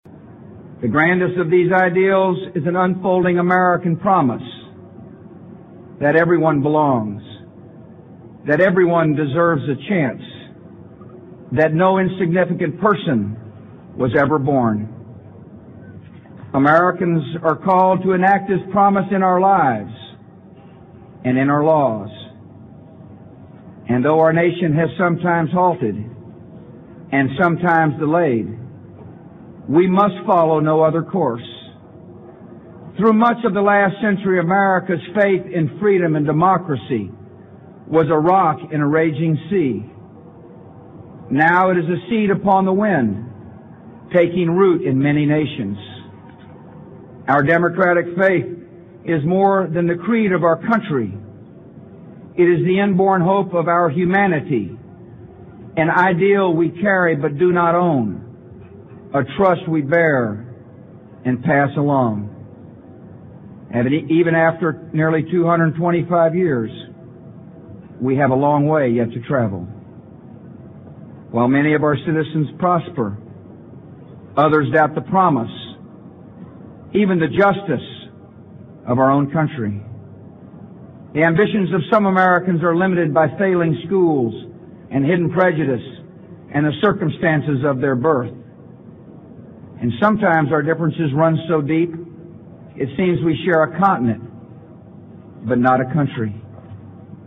名人励志英语演讲 第51期:永不疲惫,永不气馁,永不完竭(2) 听力文件下载—在线英语听力室